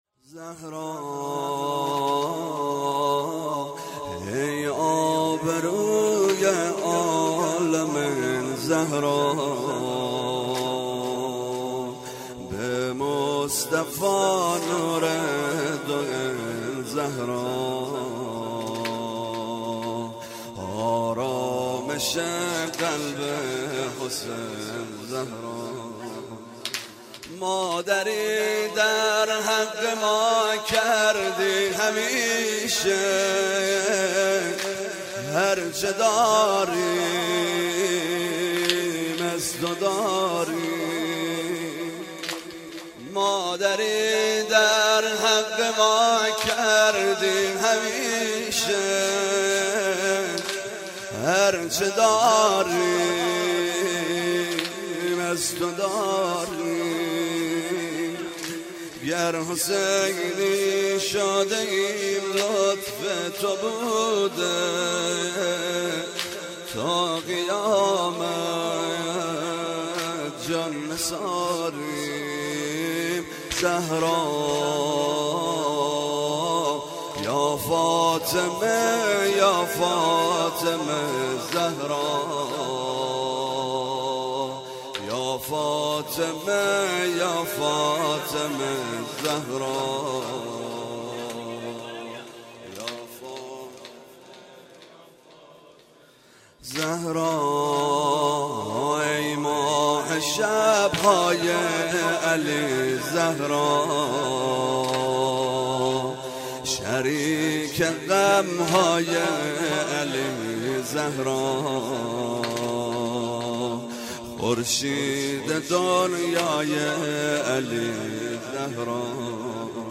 هیئت زواراباالمهدی(ع) بابلسر
0 0 سنگین - زهرا ای آبروی عالمین
شب اول ویژه برنامه فاطمیه دوم ۱۴۳۹